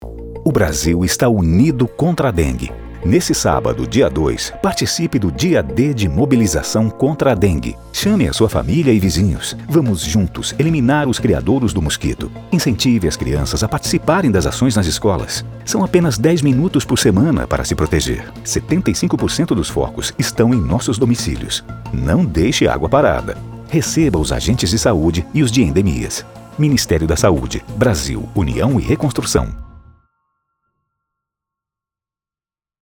Áudio - Spot 30seg - Brasil Unido Contra a Dengue - Dia D - 1,25mb .mp3